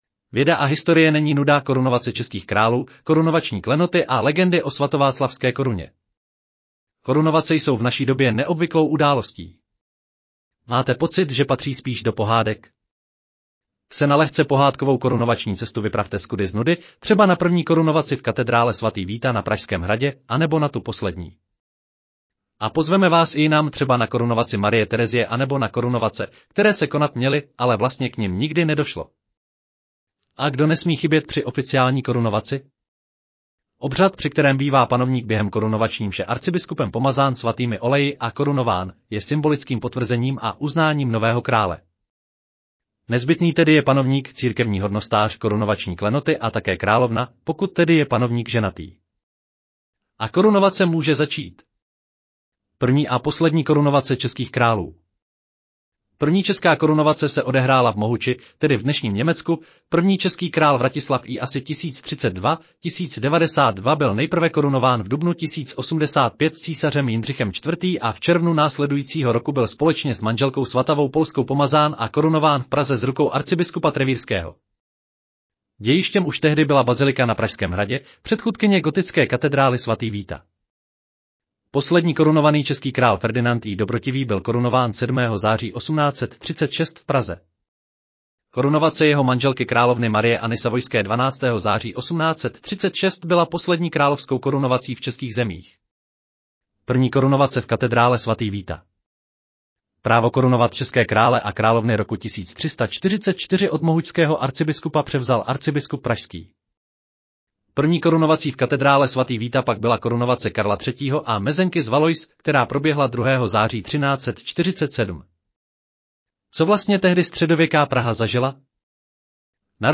Audio verze článku Věda a historie není nuda: korunovace českých králů, korunovační klenoty a legendy o Svatováclavské